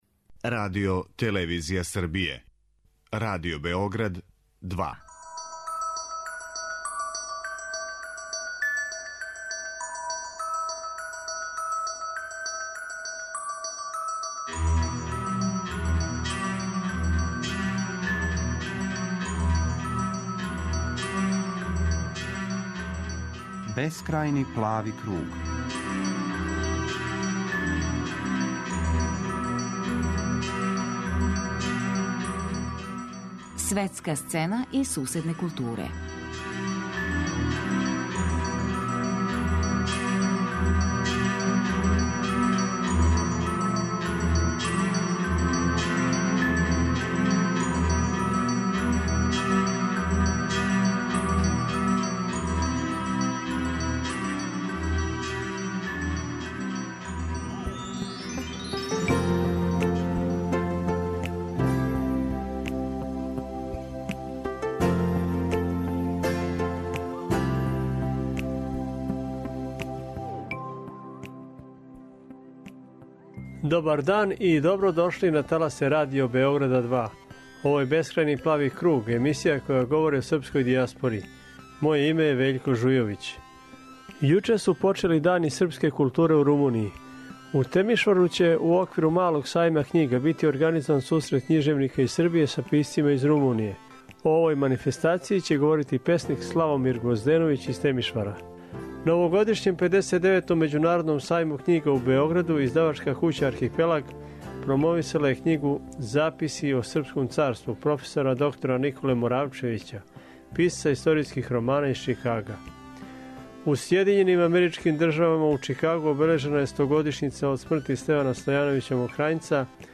Чућемо извештај нашег дописника